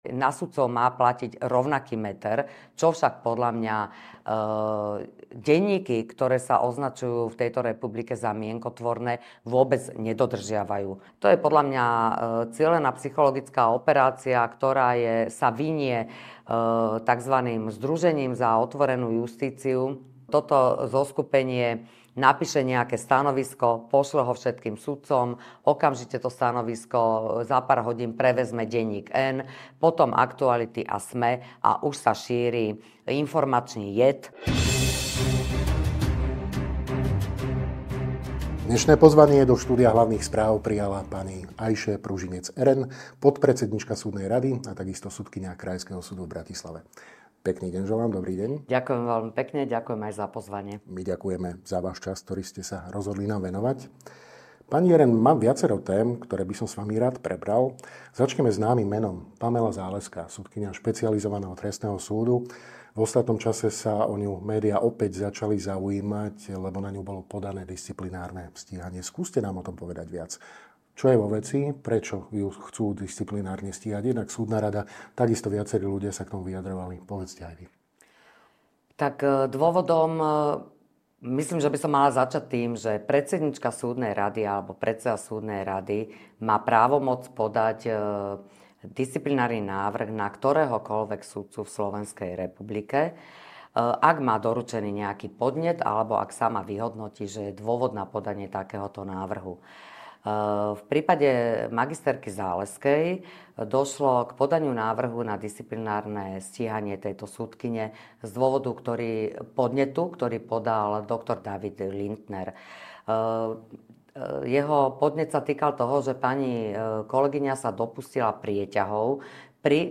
Rozprávali sme sa s podpredsedníčkou Súdnej rady a sudkyňou krajského súdu v Bratislave, JUDr. Ayše Pružinec Eren.